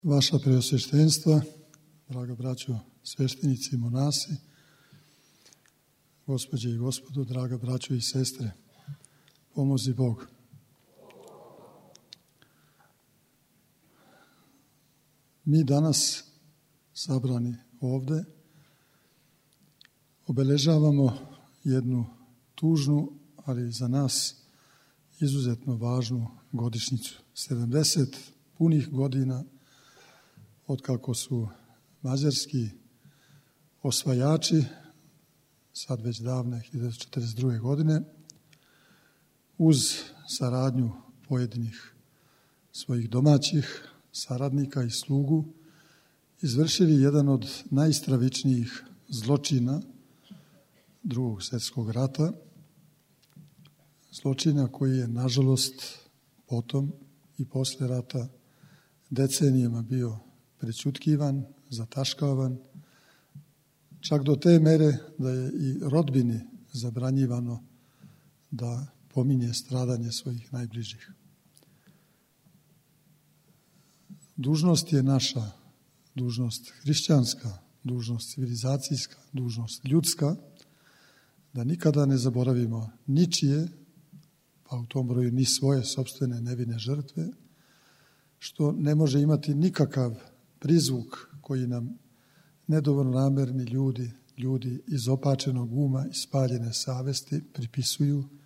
Православна Eпархија бачка наставила је молитвено обележавање седамдесетогодишњице новосадско-шајкашке Рације пригодном академијом Деца бесмртности, која је одржана у недељу, 22. јануара 2012. године, у 19 часова, у великој дворани Српског народног позоришта у Новом Саду.
Звучни запис обраћања Епископа Иринеја: